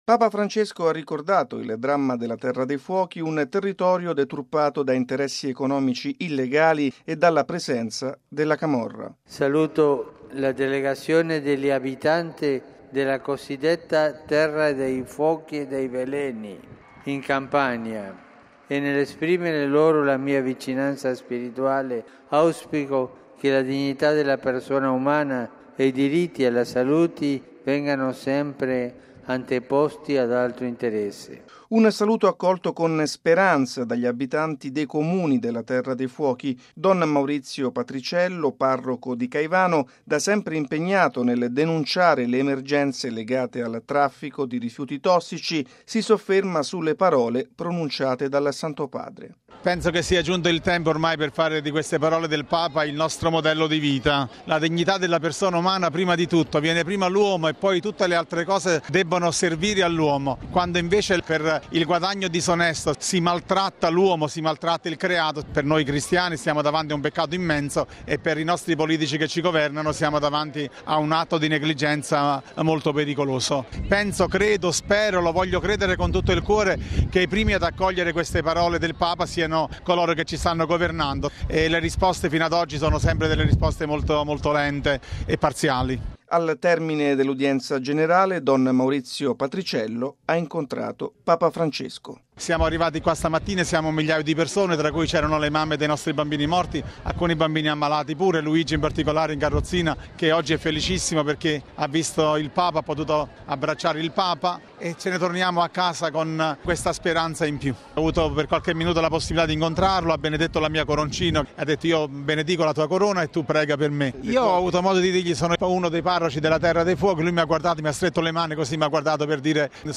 ◊   All'udienza generale, dopo la catechesi, rivolgendosi ai pellegrini italiani, Papa Francesco ha salutato la delegazione degli abitanti della “terra dei fuochi” in Campania.